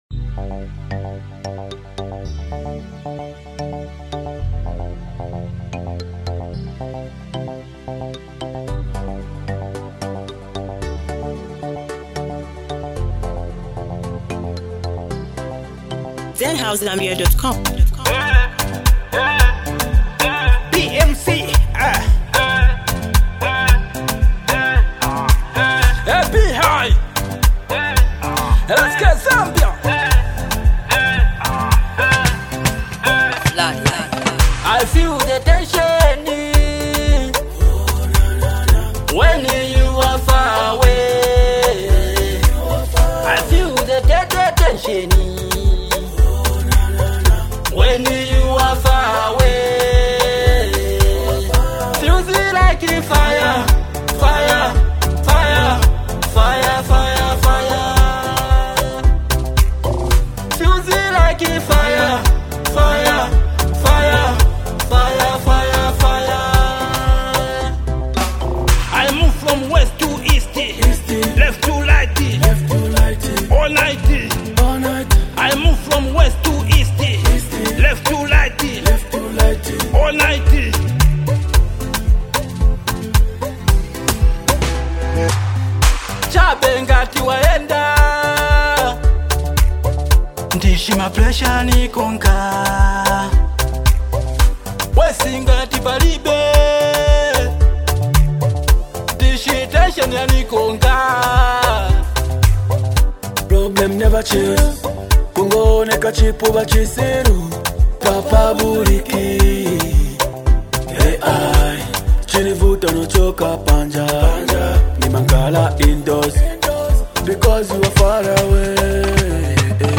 delivers pure energy and real vibes